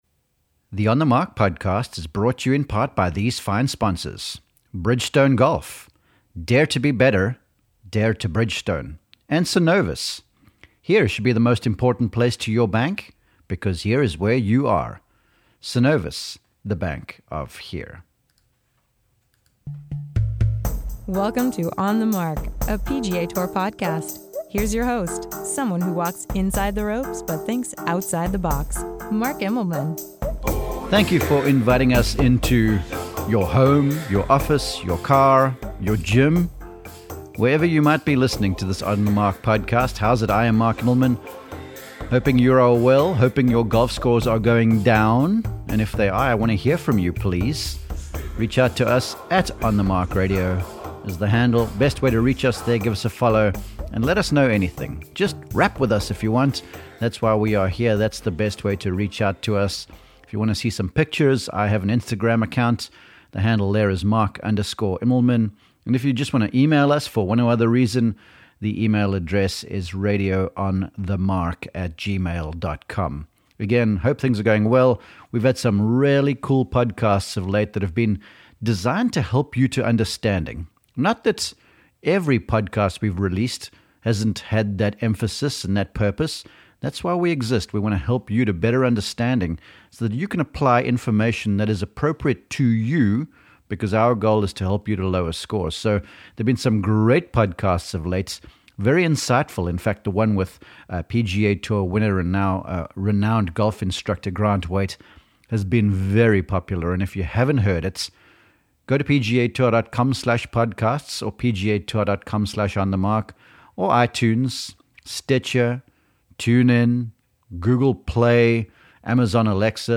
Radio and Website Interviews